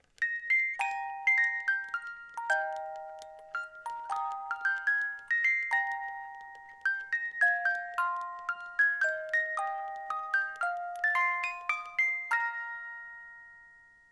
die Spieluhr besitzt ein Qualitätsspielwerk mit 18 Zungen